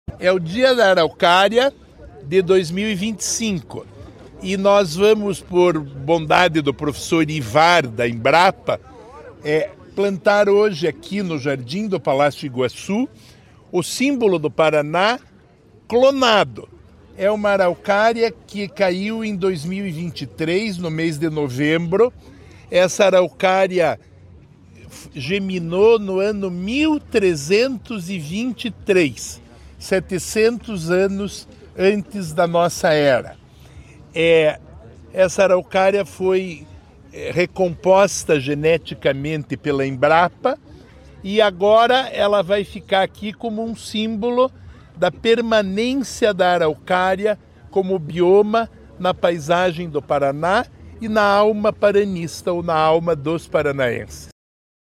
Sonora secretário do Desenvolvimento Sustentável, Rafael Greca, sobre o plantio de clone de araucária de 700 anos